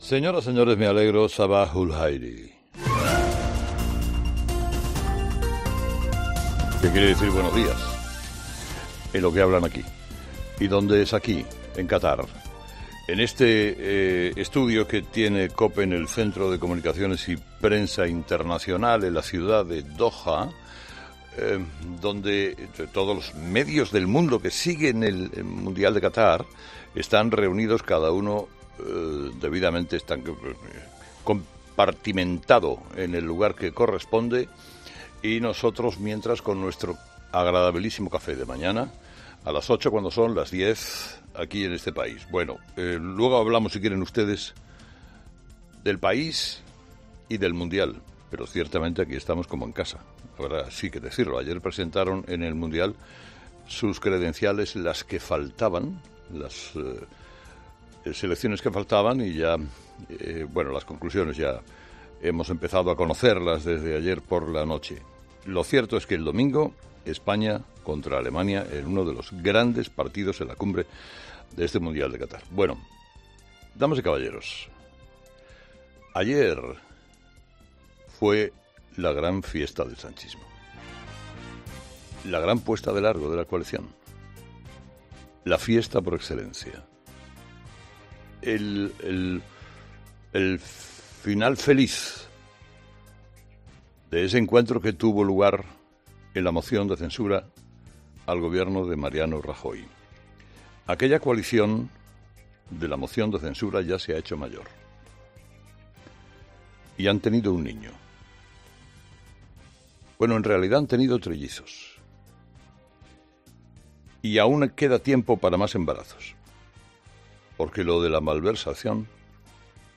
Carlos Herrera, desde el set de COPE en Qatar, a dos días del partido en la cumbre entre España y Alemania